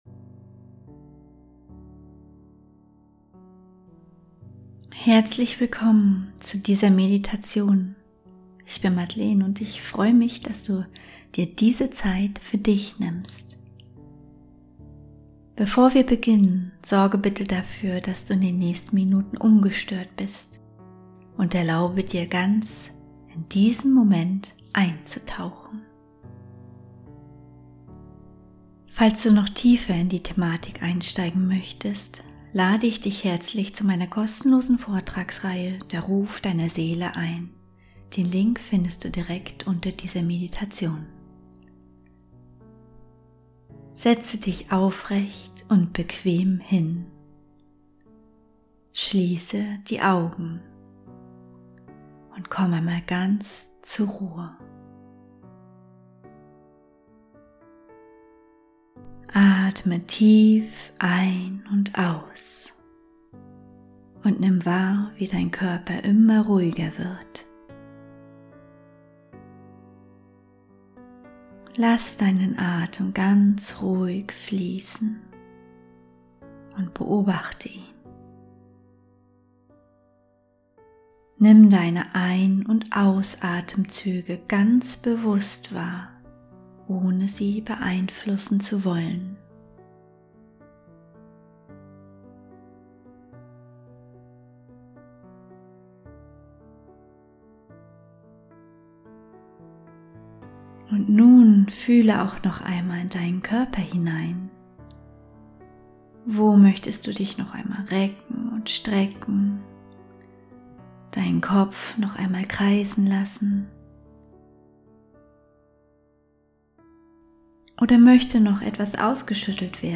18-Minuten Meditation: Aus dem Käfig der Anpassung in deine Freiheit ~ Heimwärts - Meditationen vom Funktionieren zum Leben Podcast
Willkommen zu dieser 18-minütigen geführten Meditation für innere Erlaubnis und authentischen Selbstausdruck.